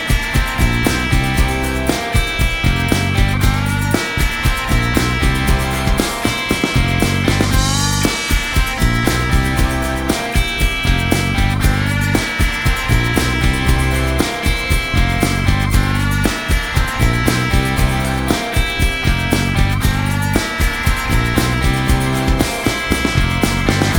no Backing Vocals Indie / Alternative 3:56 Buy £1.50